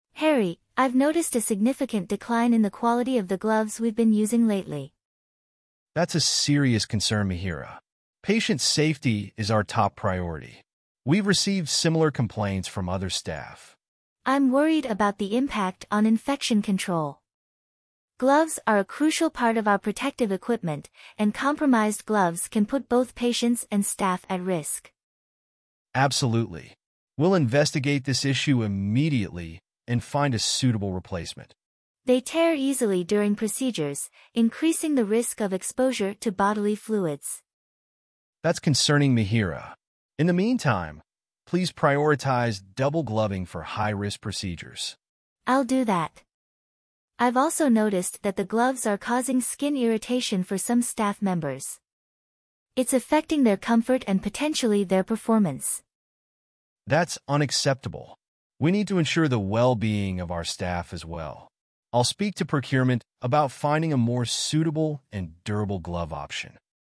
Rationale: The conversation directly addresses the concern that low-quality gloves can tear easily, increasing the risk of exposure to bodily fluids.